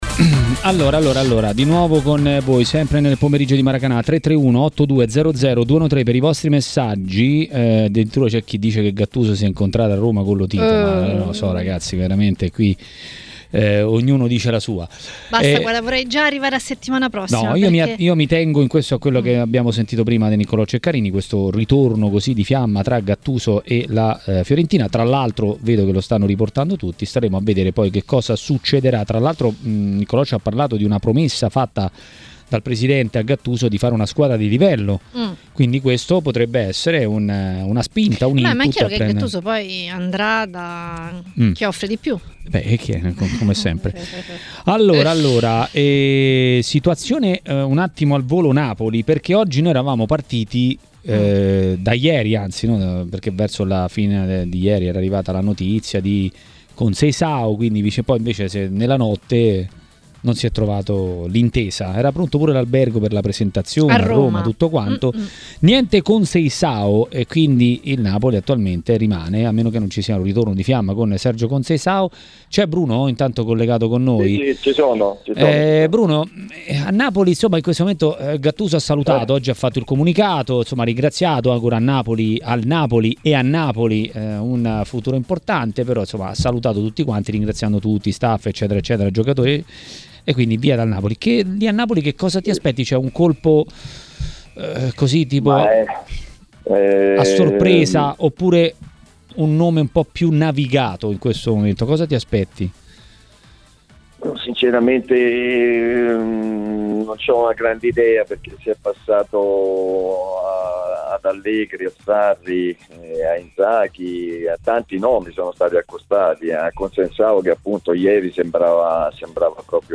A commentare le notizie del giorno a TMW Radio, durante Maracanà, è stato l'ex bomber e tecnico Bruno Giordano.